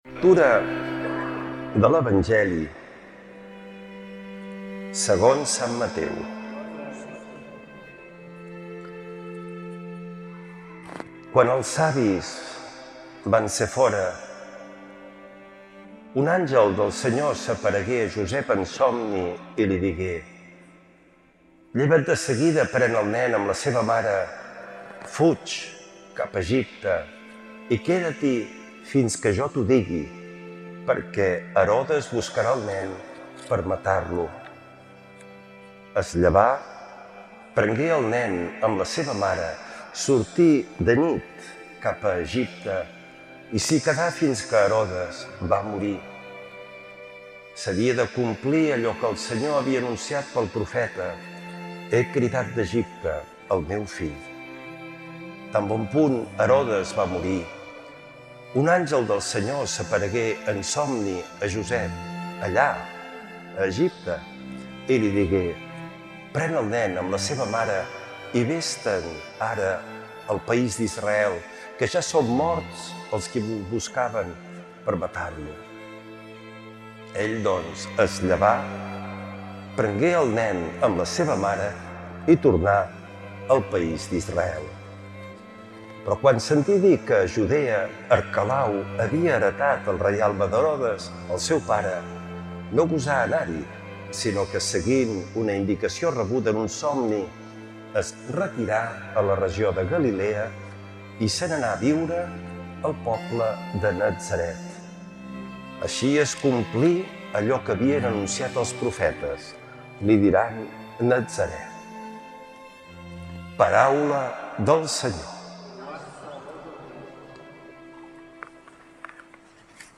Lectura de l’evangeli segons sant Mateu